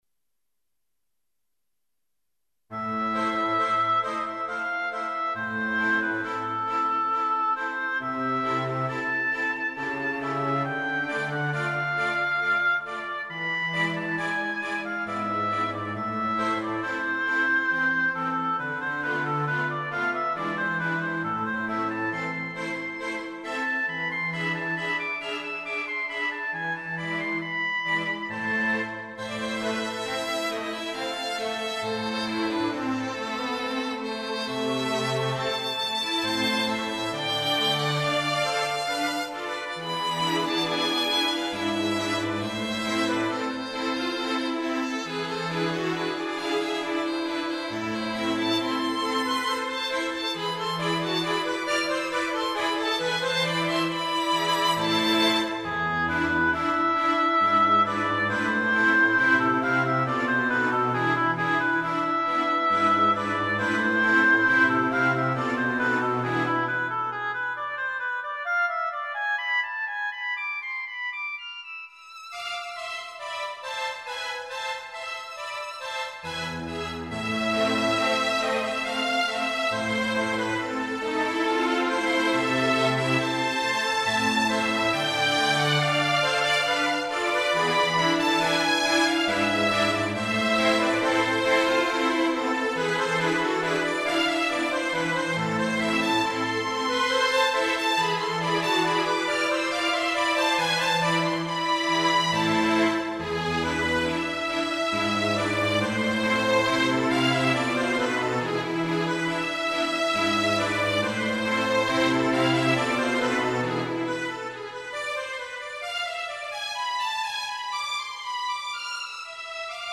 M. K. Oginski - Farewell to the Homeland (Polonaise).
I made it with "Miroslav Philharmonik"
CLASSICAL MUSIC ; ROMANTIC MUSIC